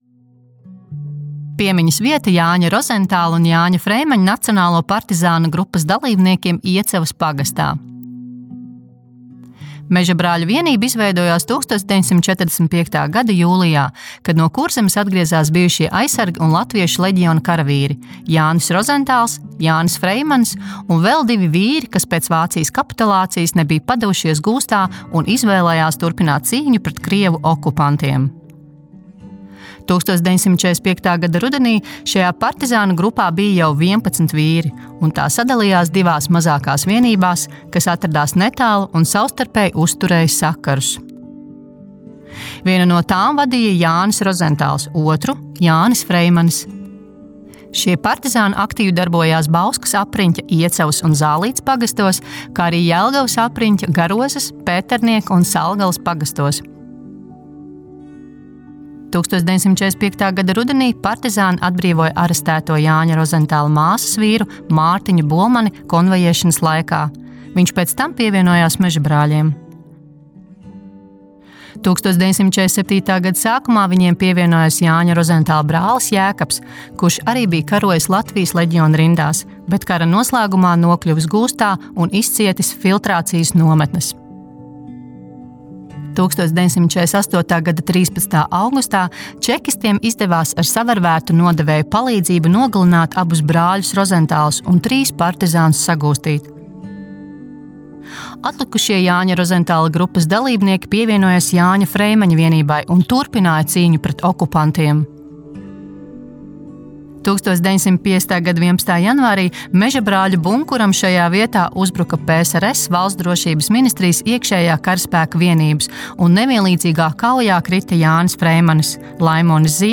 AUDIO STĀSTĪJUMS